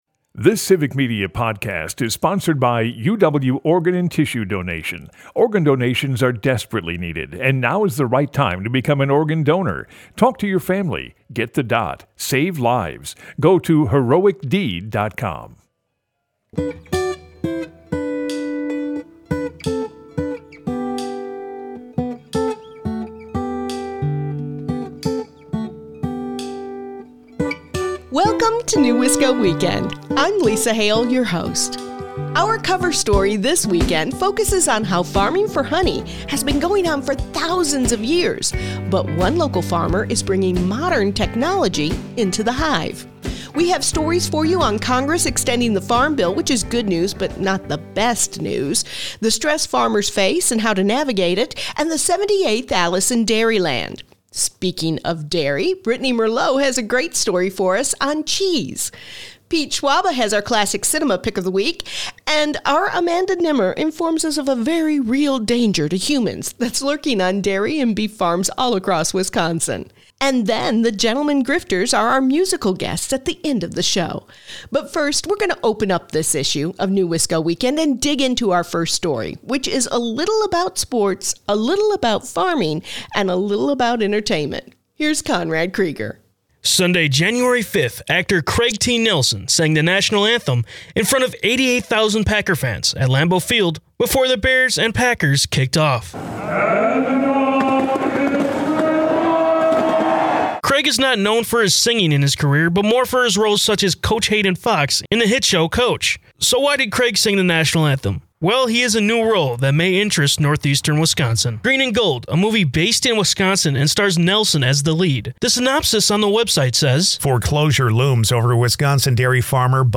NEWisco Weekend is a news magazine-style show filled with conversations and stories about issues, entertainment, and culture making the Fox Valley, Green Bay and beyond -- a rich, unique area to call home.